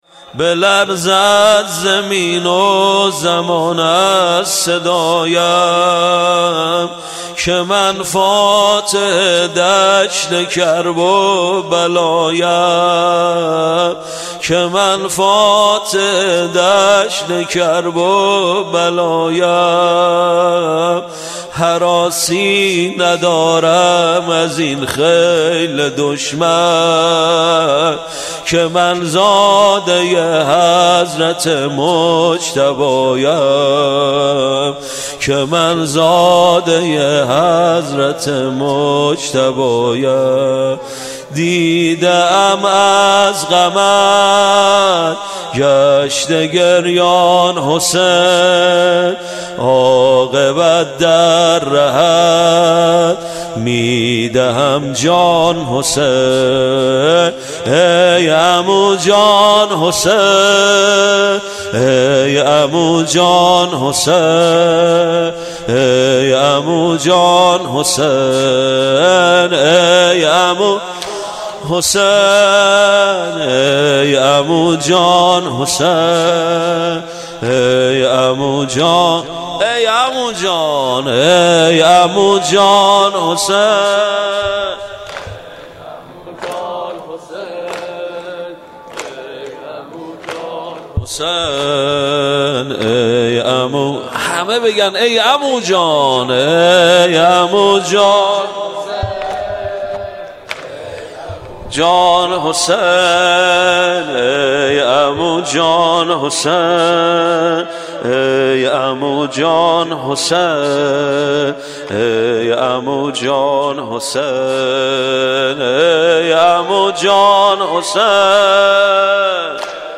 مداحی شب ششم محرم ۹۹ مدرسه فیضیه - هیئت ثارالله قم
sinezani.mp3